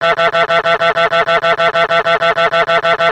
He also honks, A LOT.
Simulation of “Desktop Goose” honking.
hjonk.mp3